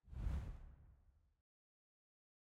sfx_cutscene_art_2.ogg